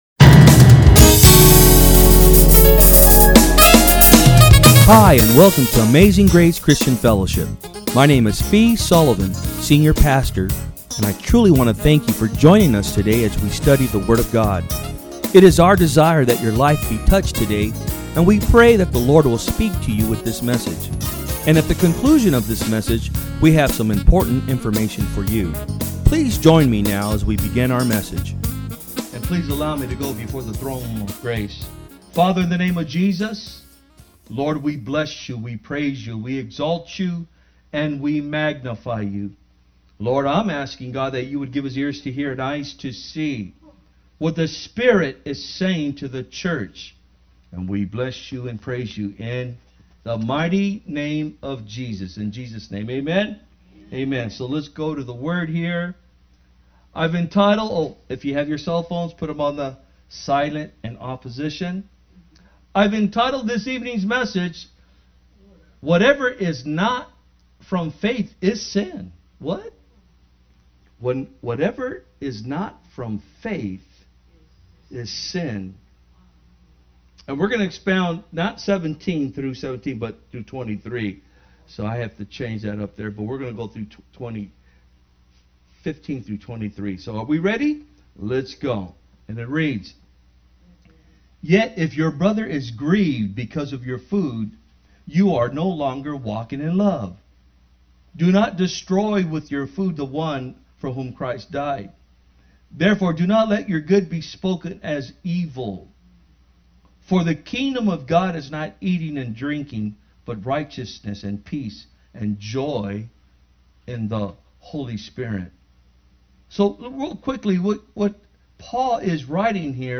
Sermons
From Service: "Wednesday Pm"